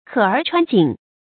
渴而穿井 kě ér chuān jǐng
渴而穿井发音